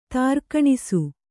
♪ tārkaṇisu